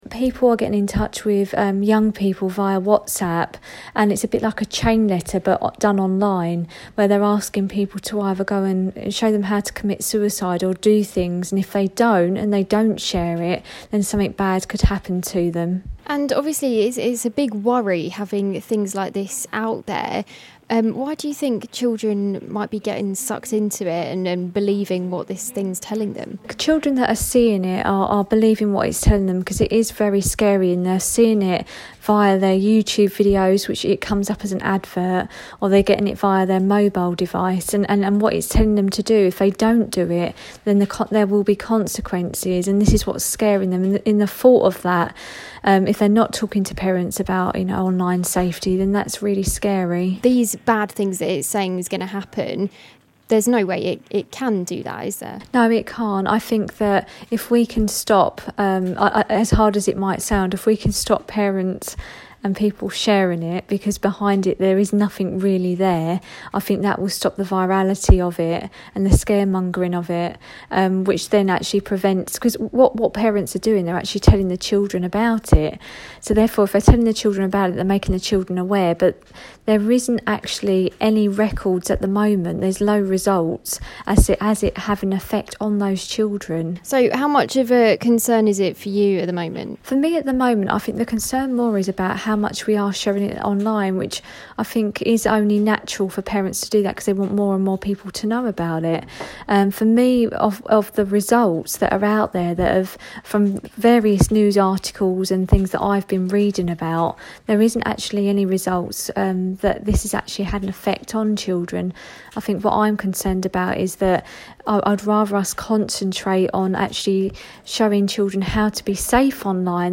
social media expert